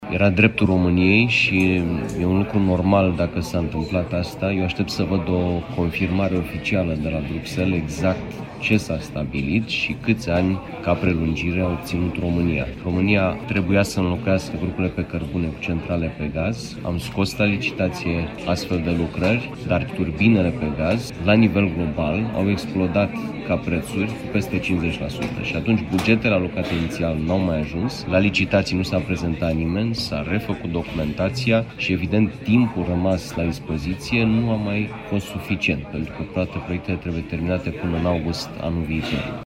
România avea dreptul să ceară prelungirea termenului de închidere a centralelor pe cărbune, stabilit inițial pentru 31 decembrie 2025. O spune chiar fostul ministru al Energiei, Sebastian Burduja.